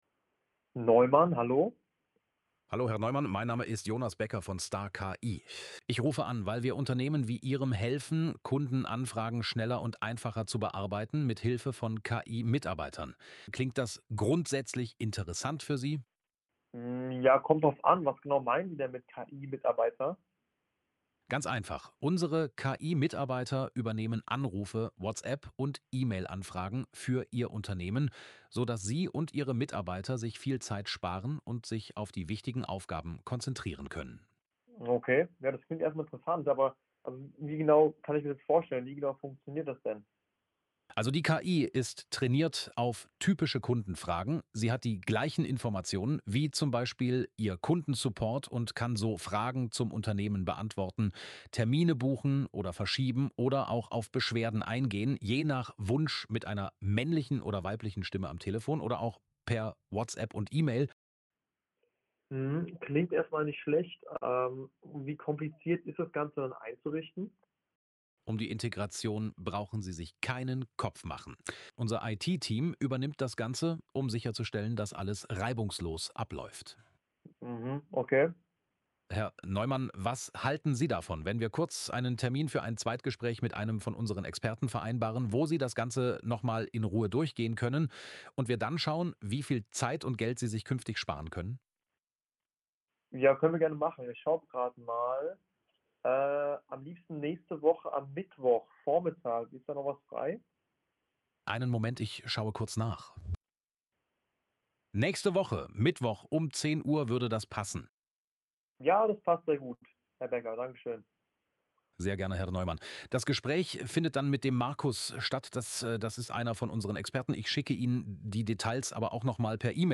Beispiele von KI Tele-Mitarbeitern